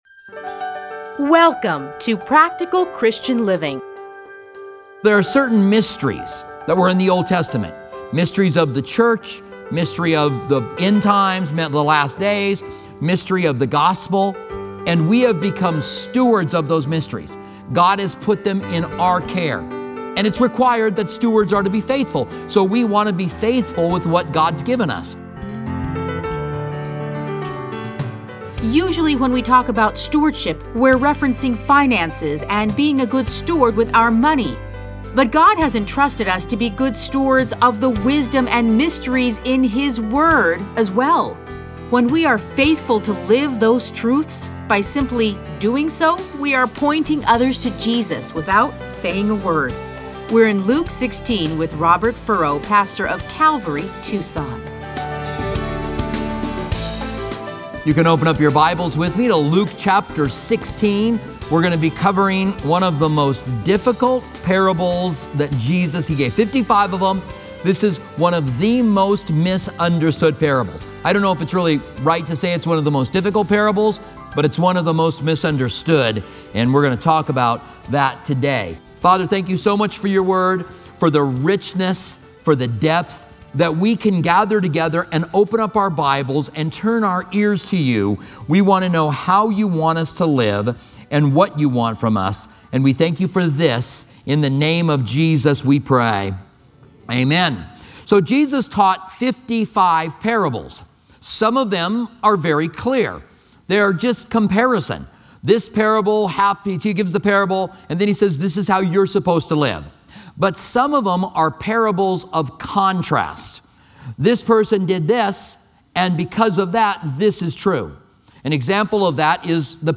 Listen to a teaching from Luke 16:1-18.